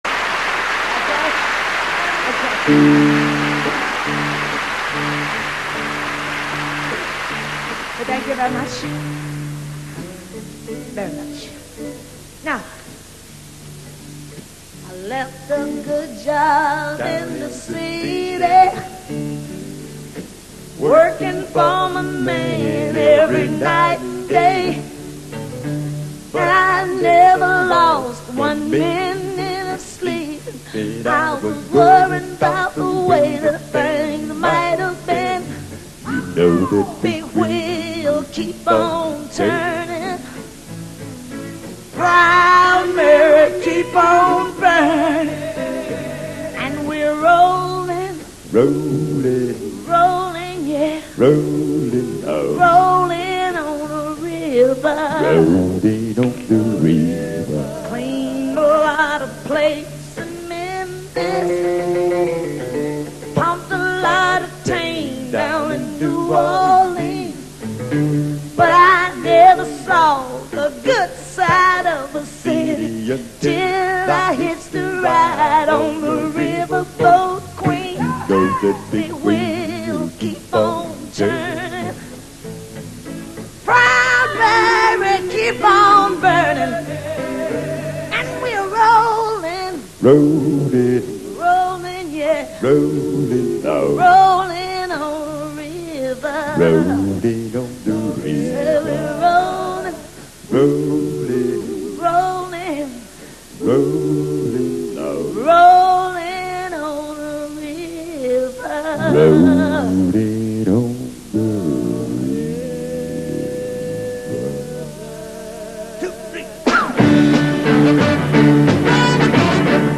(live on Italian TV 1971)